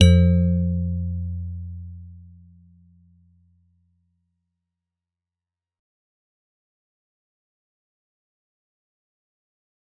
G_Musicbox-F2-f.wav